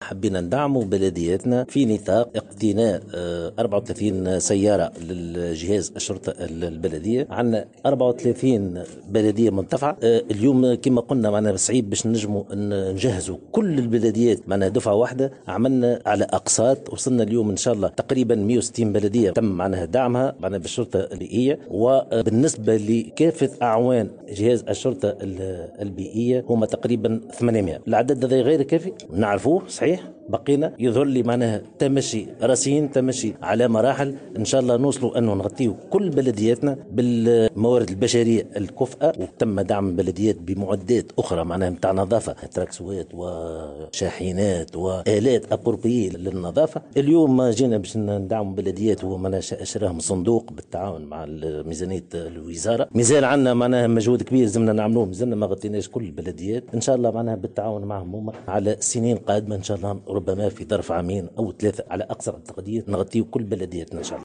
واضاف في تصريح لموفدة "الجوهرة أف أم" على هامش حفل توزيع سيارات الشرطة البيئية بمقر وزارة الشؤون المحلية، أن العدد الجملي لاعوان الشرطة البيئية يبلغ حاليا 800 عون شرطة بيئية وأن الوزارة تسعى لتغطية كل البلديات بالموارد البشرية على مستوى هذا الجهاز في اطار "تمشّي مرحلي"، وفق تعبيره.